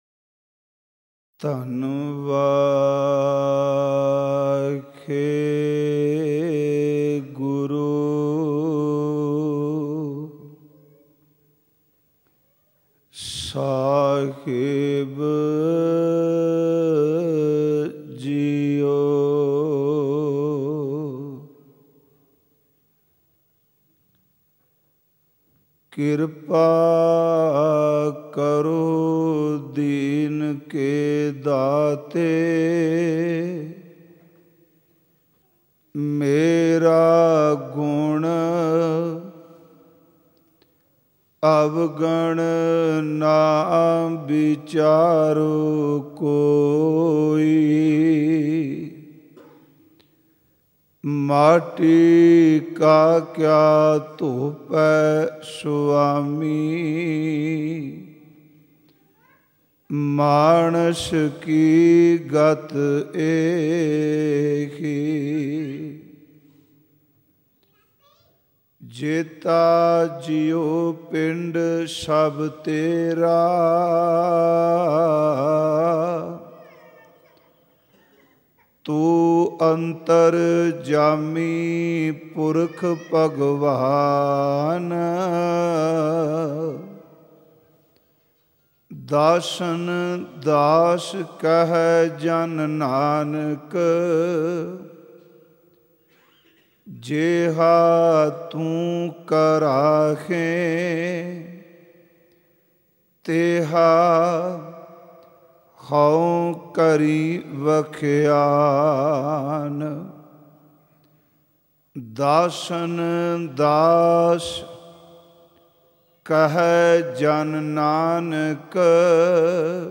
NAAM JAPHO MERE SAJAN SAINA {LIVE-3-8-06-PM}.mp3